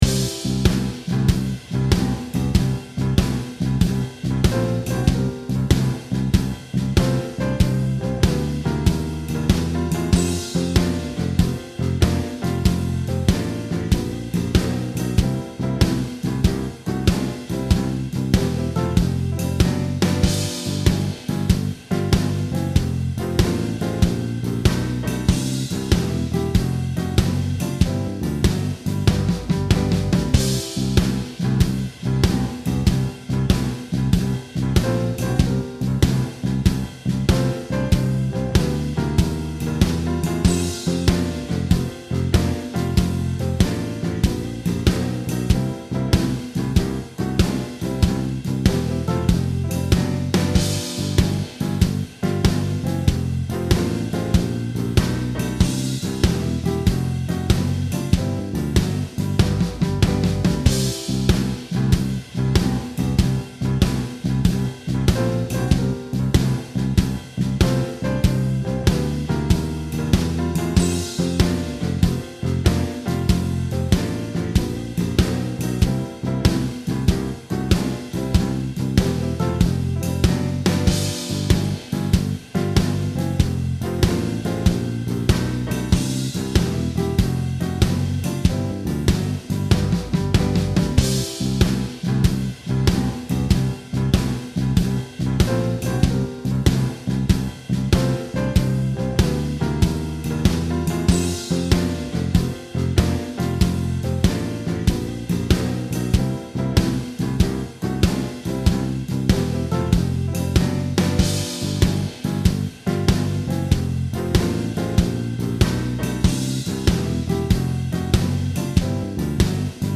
Guitar Lessons: Alternative Chord Voicings